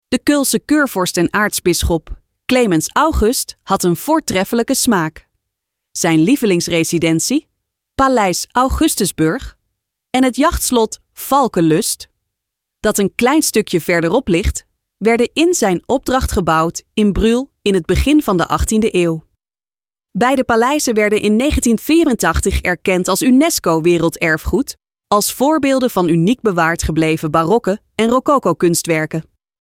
audiogids-nederlands-br-hl-paleizen.mp3